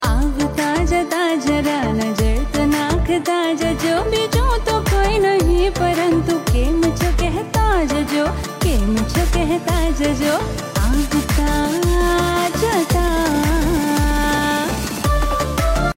Categories Indian Festival Ringtones